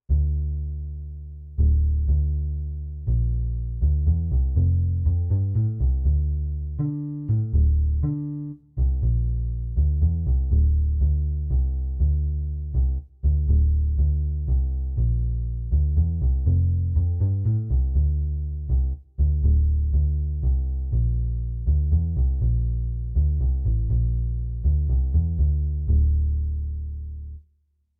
盛り上がりでベースの入れ方を変えています。
ベースはアコースティックベースを使用します。